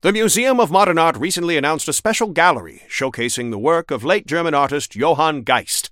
Newscaster_headline_69.mp3